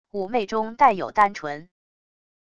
妩媚中带有单纯wav音频